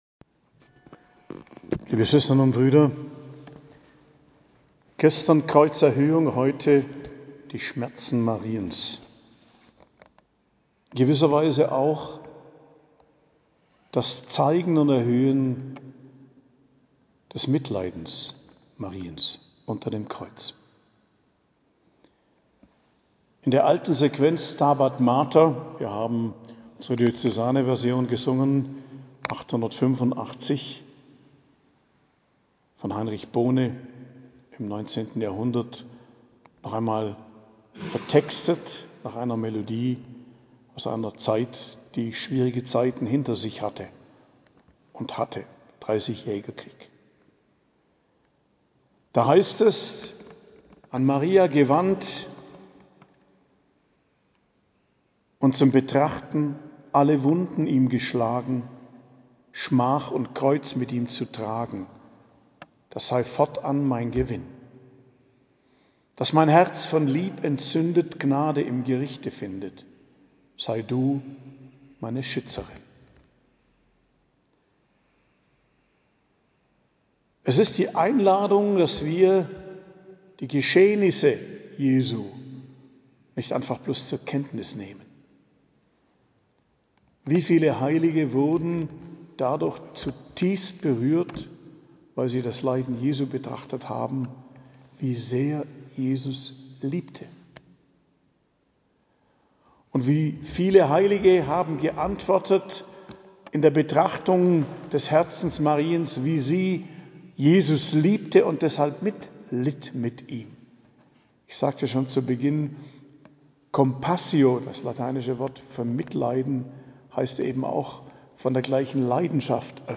Predigt am Tag des Gedächtnis der Schmerzen Mariens 15.09.2023 ~ Geistliches Zentrum Kloster Heiligkreuztal Podcast